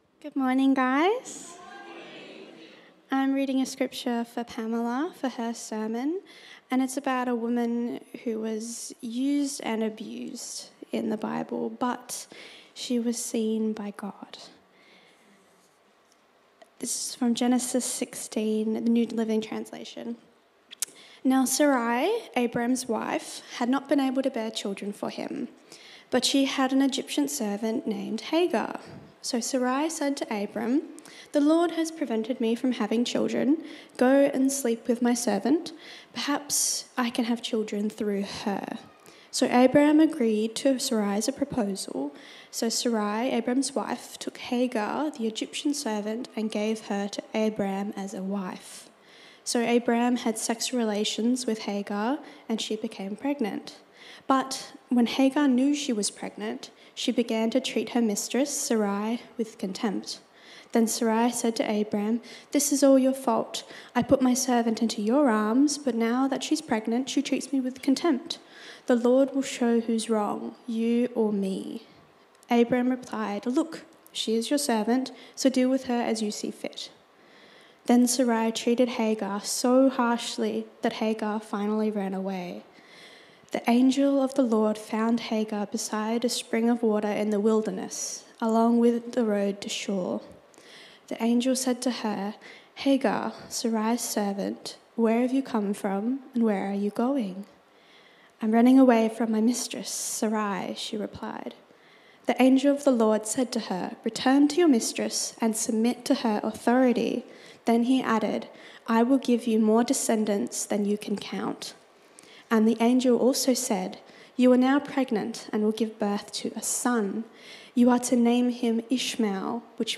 Sermon Transcript Genesis 16